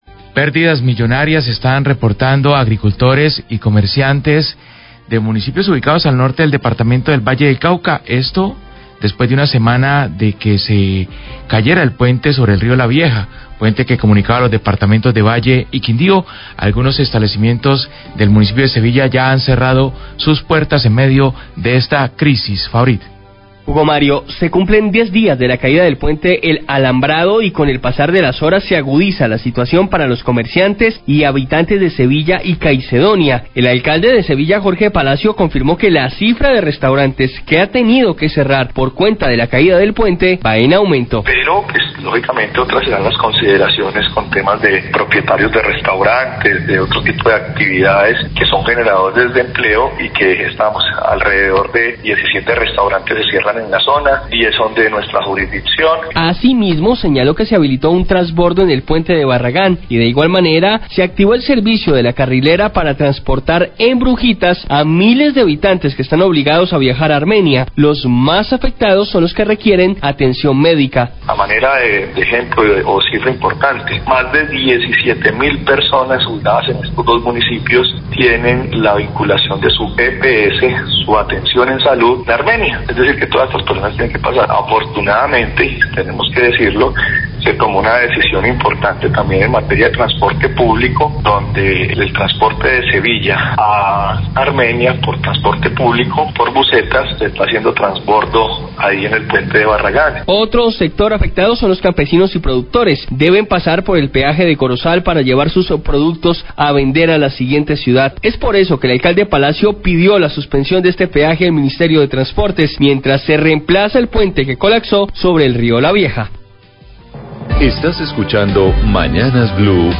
El Alcalde de Sevilla, Jorge Augusto Palacio, habla sobre las pérdidas económicas de agricultores y comerciantes del norte del Valle tras 10 días de la caída del puente del Alambrado. Además pidió la suspensión del peaje de Corozal por donde los campesinos deben pasar sus productos para vender en otras ciudades.